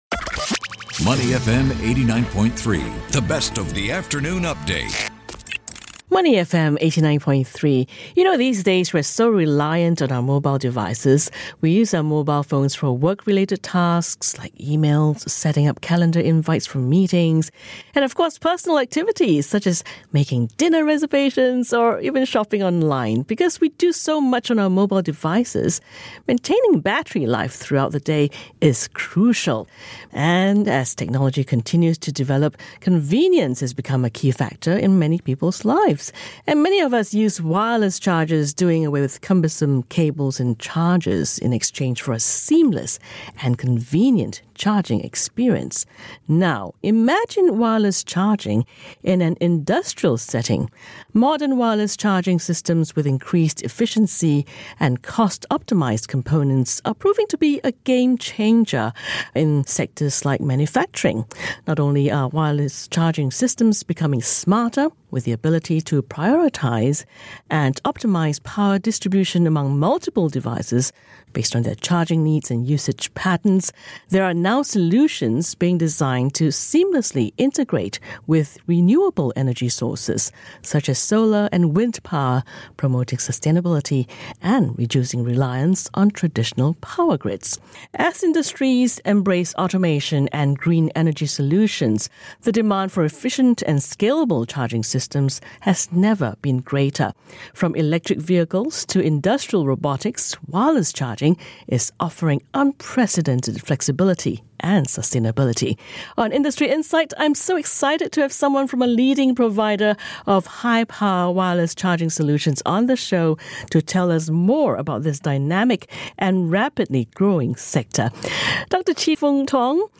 ラジオインタビュー：ロボットとEVのワイヤレス充電 - Xnergy | High Power Contactless Charging